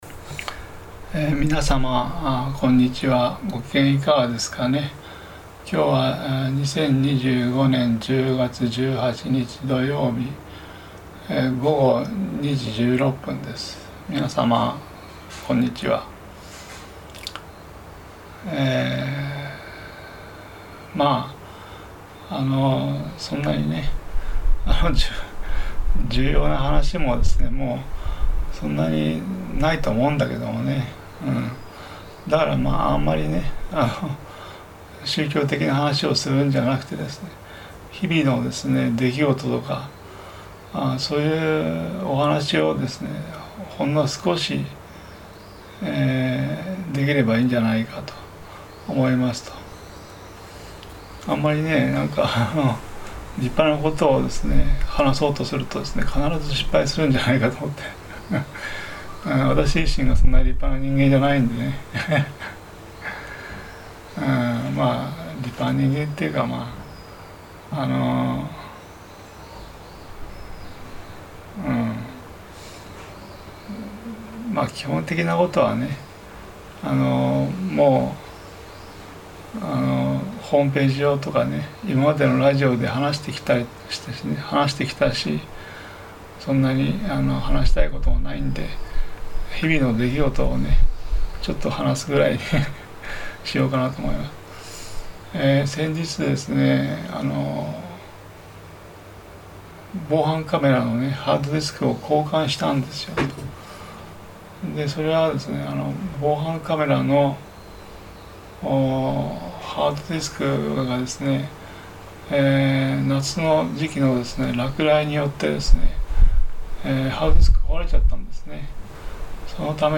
扇風機がマイクに当たると、音が消えてしまいますね。
聴きづらくて、申し訳ないです。